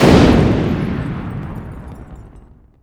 Index of /90_sSampleCDs/AKAI S6000 CD-ROM - Volume 1/SOUND_EFFECT/EXPLOSIONS
BIG EXPLOD.WAV